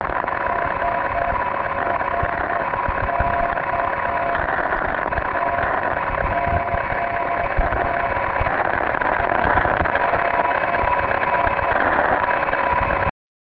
A bit farther afield was the beacon "SUK" (listen to that one